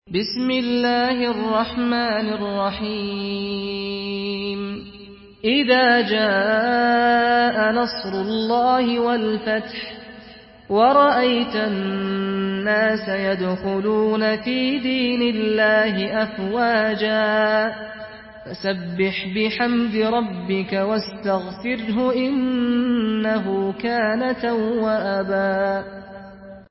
Surah Nasr MP3 by Saad Al-Ghamdi in Hafs An Asim narration.
Murattal Hafs An Asim